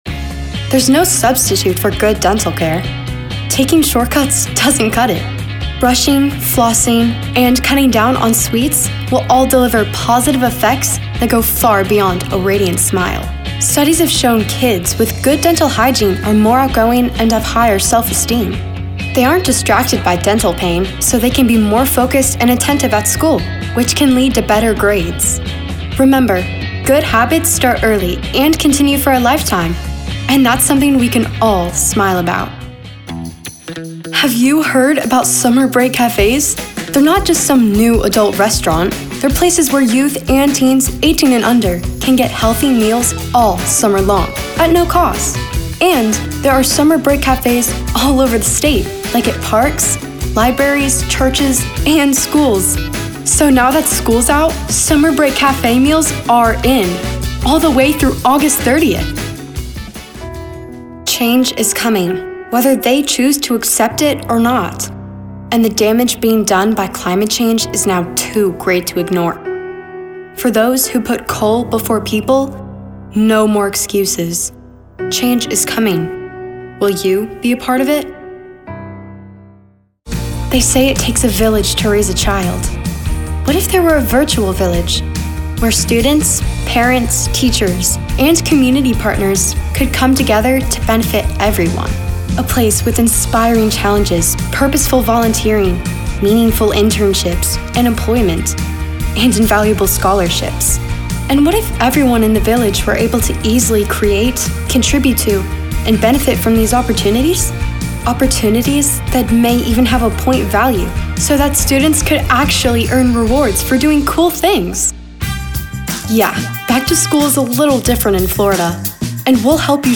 From soft spoken to energetic, her versatility shines through!